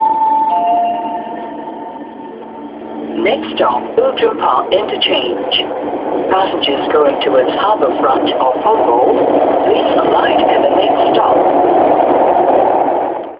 mehr als deutlich durchgesagt (Beispiel: erste Ansage zu »Outram Park« auf der East-West-Linie).